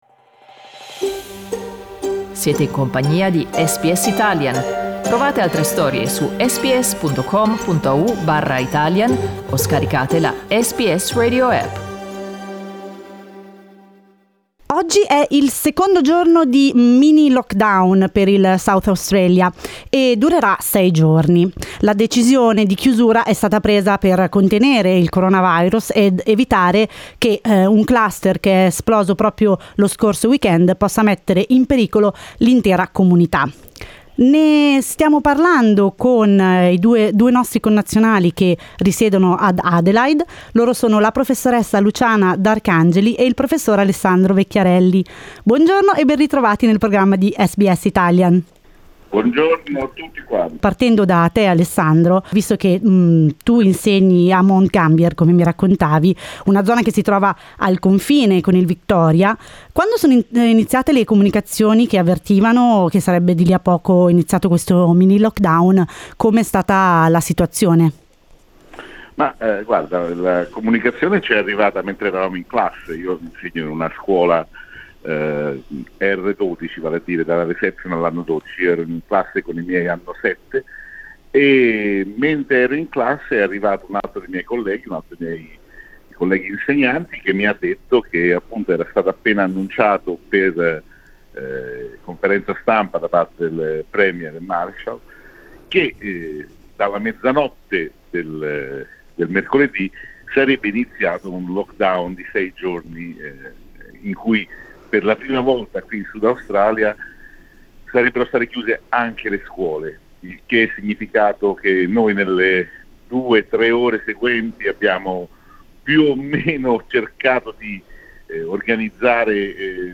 Durante la giornata di giovedì 19 novembre, abbiamo raggiunto telefonicamente due nostri connazionali per avere degli aggiornamenti sulla situazione in South Australia.
come ci ricorda anche in questa intervista